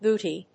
音節boo・tie 発音記号・読み方
/buːtíː(米国英語), ˈbu:ti:(英国英語)/